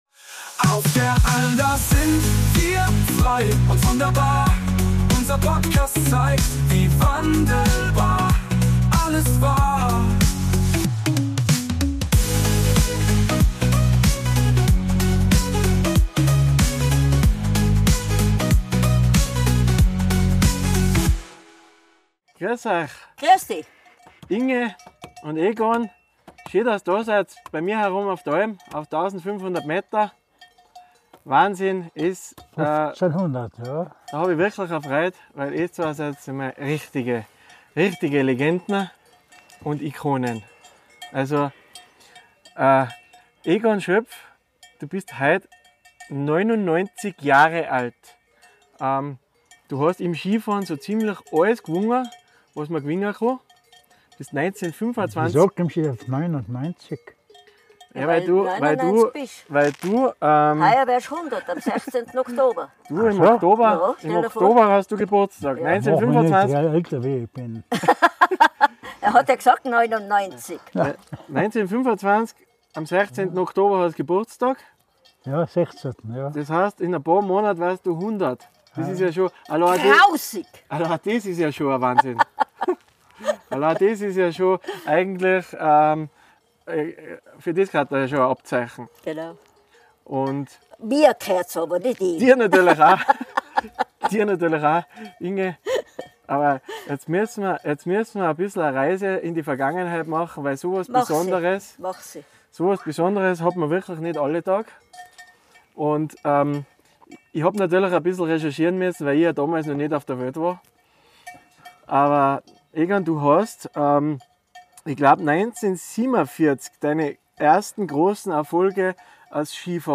Ein herzerwärmendes Gespräch über Leidenschaft, Zusammenhalt und den Zauber des einfachen Lebens in den Tiroler Bergen.